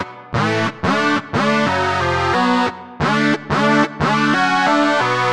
描述：在我学习音乐回家的路上，我注意到每次从车站出发都有相当讨厌的频率。你可以听到车门被打开和关闭的声音，在汽车之间的嘈杂的地方。用膝上型电脑的内置麦克风录制的。
标签： 低价格的机票 门大满贯 离开 加速 里面 火车 呜呜 瑞典
声道立体声